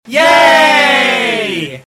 Download Yay sound effect for free.